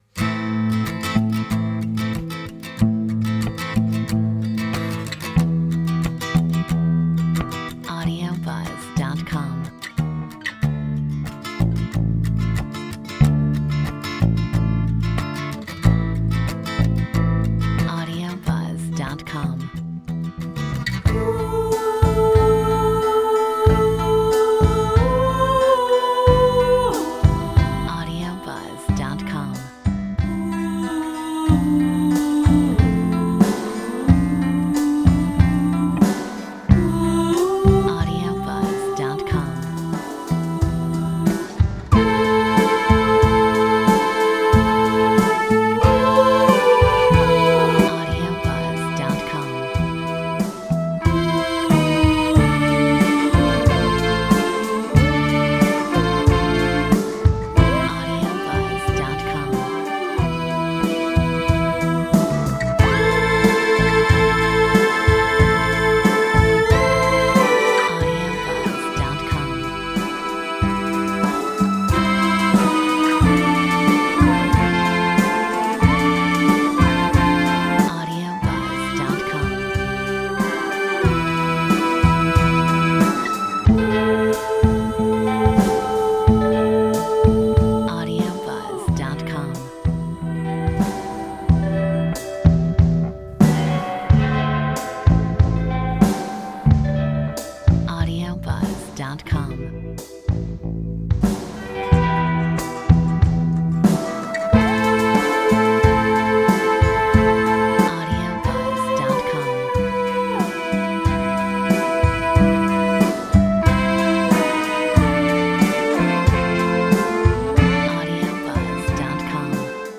Metronome 92